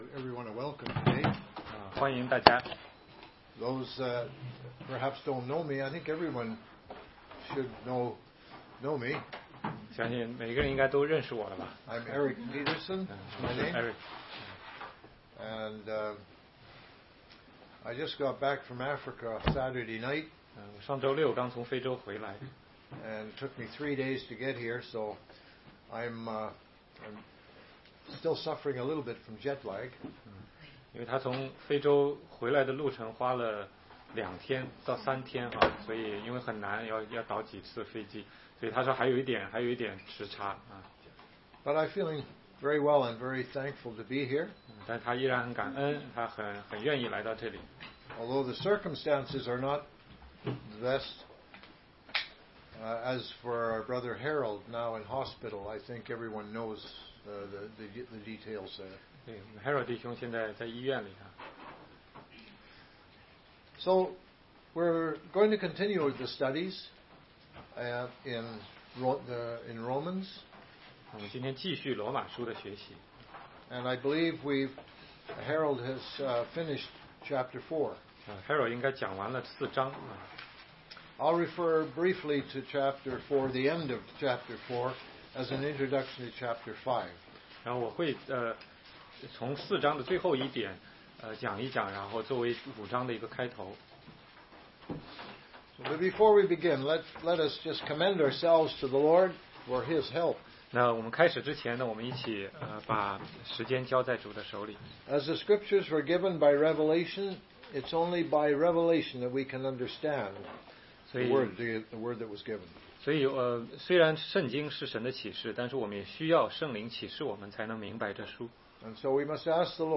16街讲道录音 - 罗马书5章1-11节